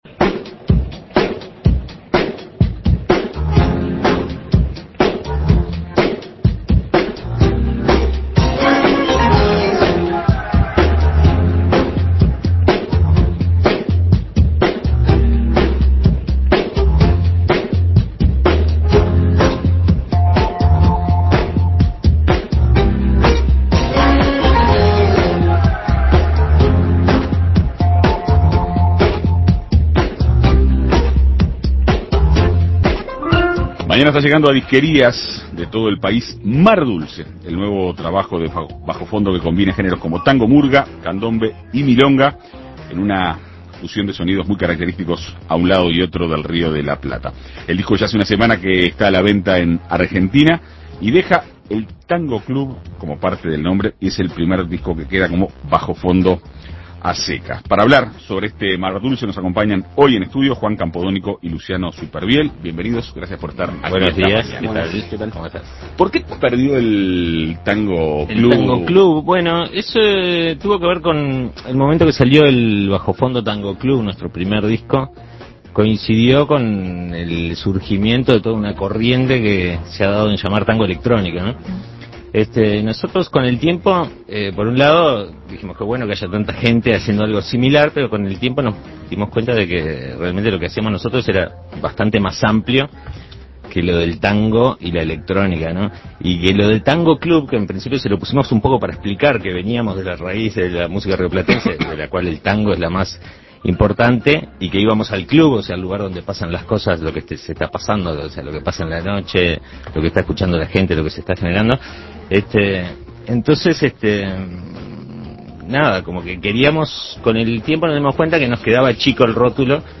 Escuche la entrevista con Juan Campodónico y Luciano Supervielle, integrantes de Bajofondo.
Tango, murga, candombe y milonga son los ingredientes de "Mar dulce", el nuevo disco de Bajofondo, que estará a la venta en disquerías a partir de este miércoles 12. Juan Campodónico y Luciano Supervielle dialogaron con En Perspectiva Segunda Mañana.